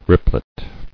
[rip·plet]